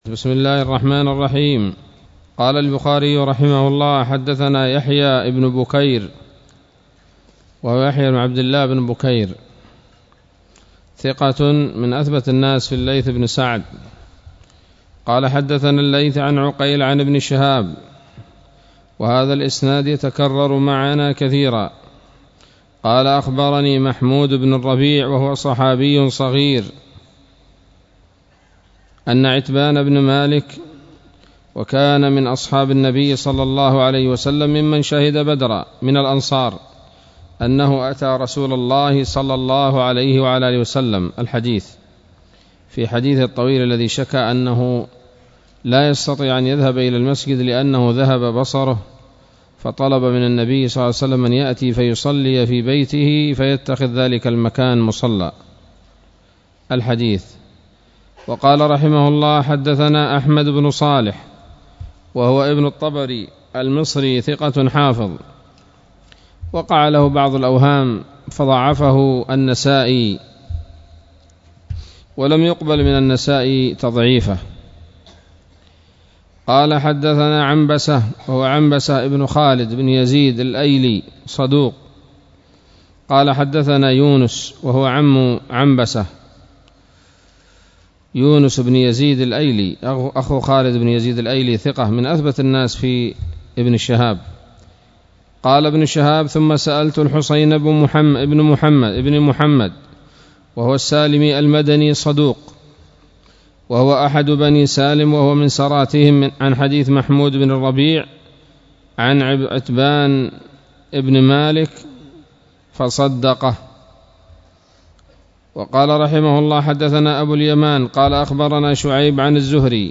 الدرس الحادي والعشرون من كتاب المغازي من صحيح الإمام البخاري